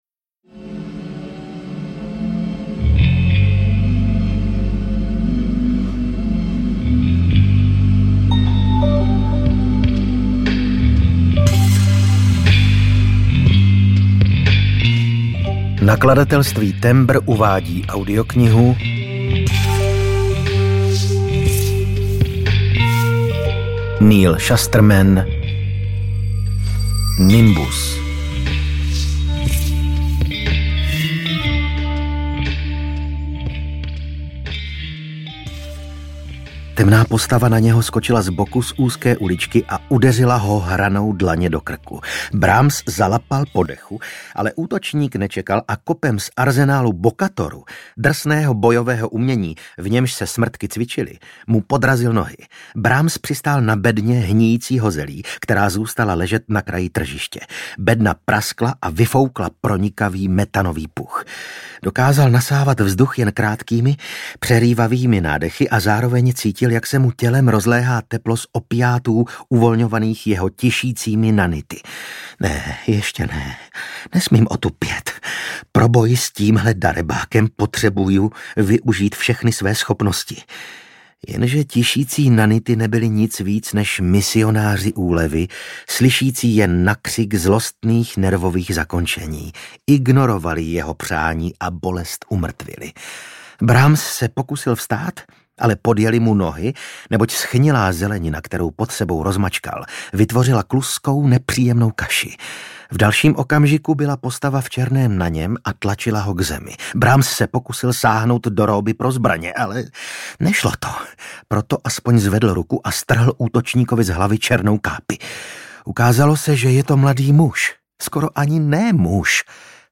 Nimbus audiokniha
Ukázka z knihy